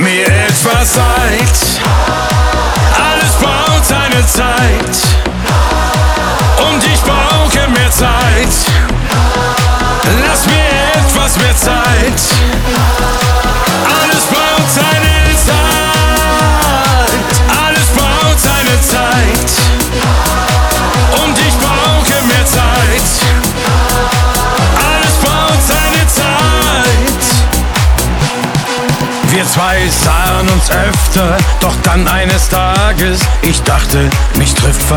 German Pop
Жанр: Поп музыка